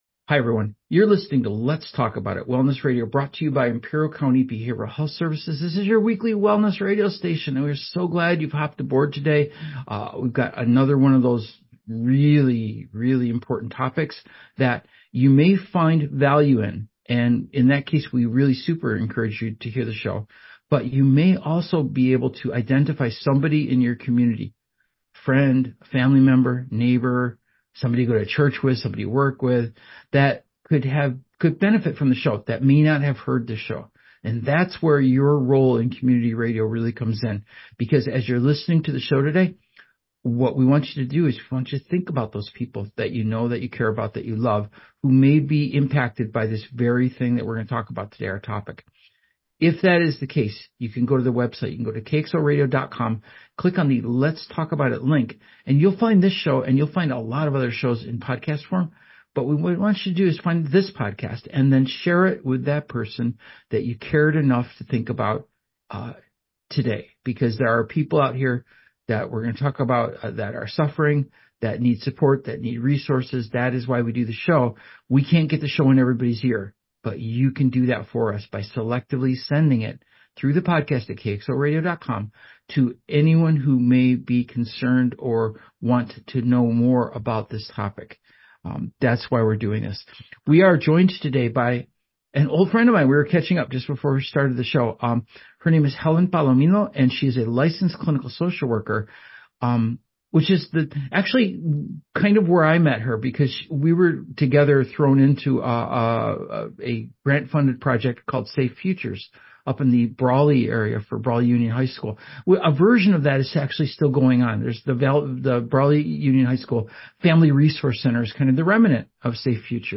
Talk Radio/Behavioral health